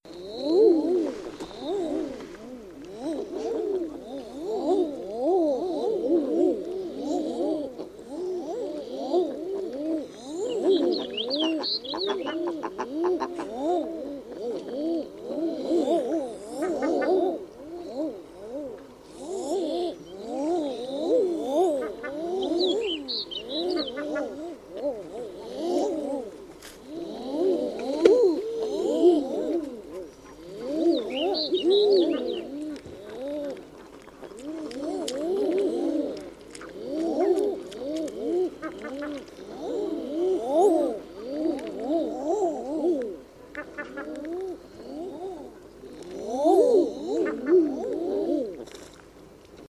• common eiders in tundra.wav
common_eiders_in_tundra_XkX.wav